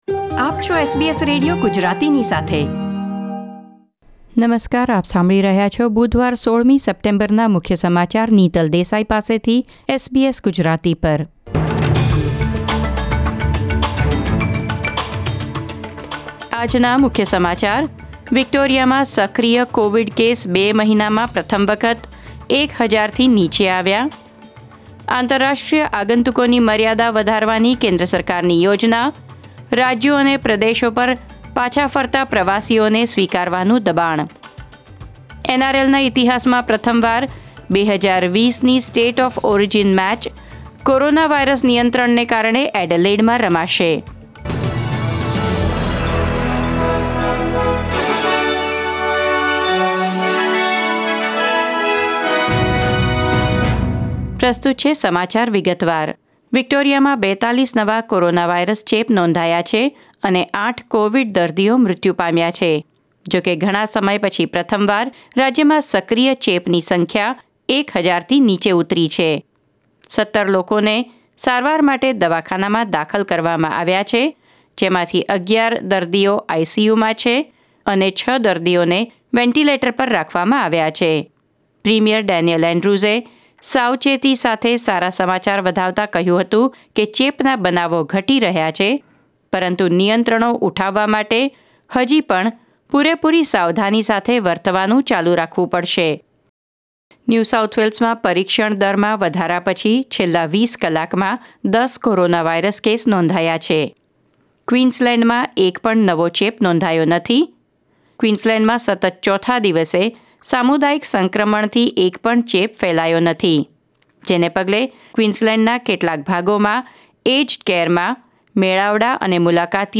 SBS Gujarati News Bulletin 16 September 2020